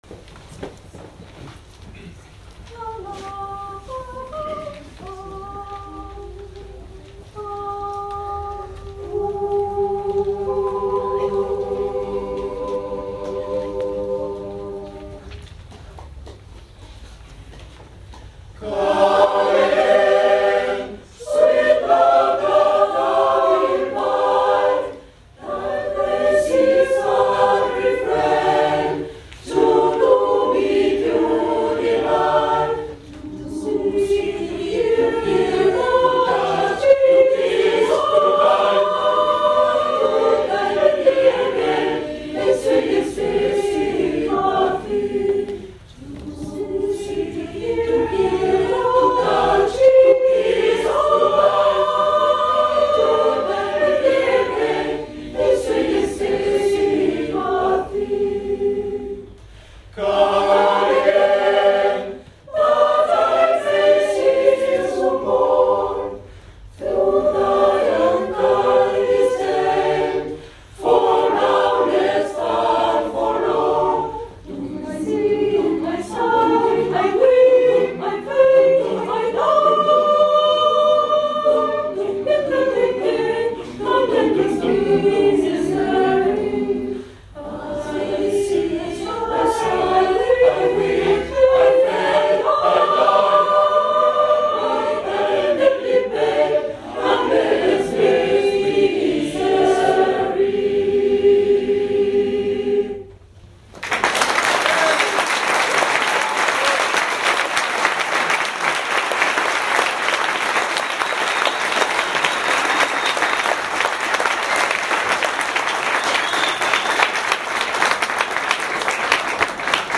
Cantamos en el TEATRO SAN JOSÉ